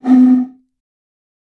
Blow Into Pipe